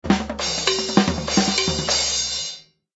ring_perfect.ogg